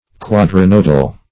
Search Result for " quadrinodal" : The Collaborative International Dictionary of English v.0.48: Quadrinodal \Quad`ri*nod"al\, a. [Quadri- + nodal.]
quadrinodal.mp3